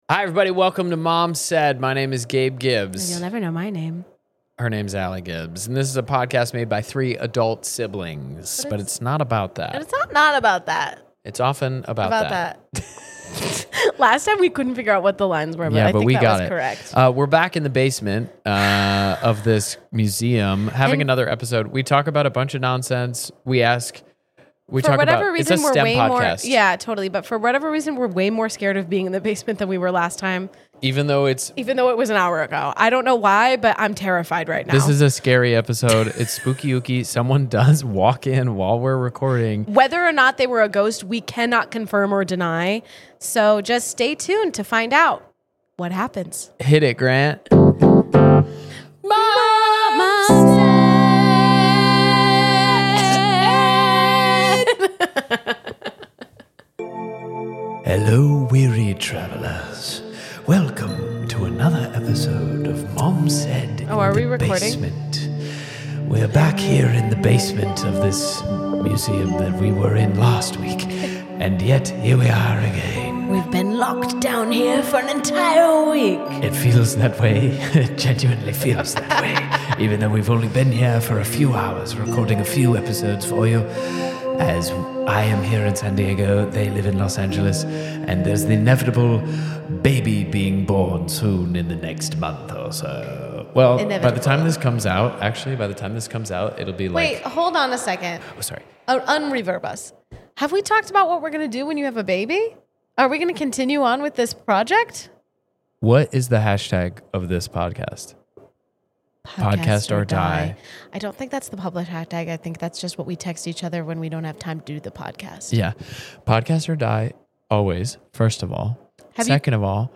We are back in the spooky basement! This episode is full of nonsense about sea creatures, church, boys, and convertibles.